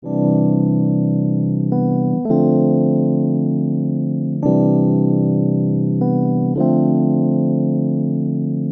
经典RnB罗兹钢琴
和弦： 基调：A大调 A7 Ab7 Cm7 Eadd6/A Fmin7 A7 Am6 E Eaug4 (?) （可能不是100正确）。
标签： 100 bpm RnB Loops Rhodes Piano Loops 3.23 MB wav Key : A
声道立体声